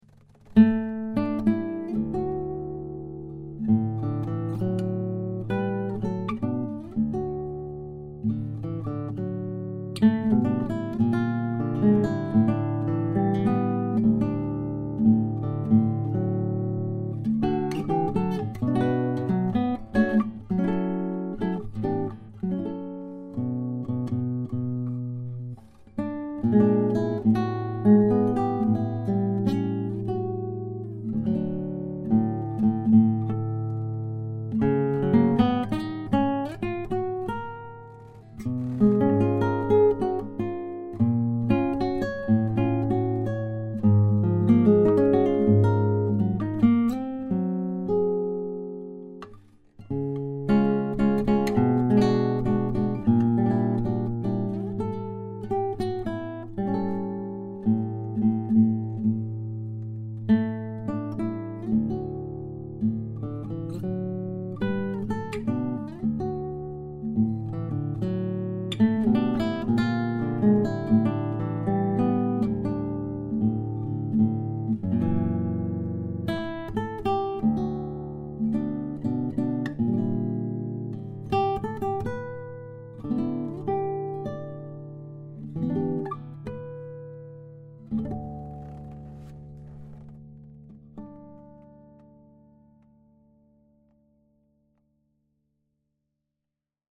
2003 Pimentel Nylon Southwestern Collection Series Indian/Spruce - Dream Guitars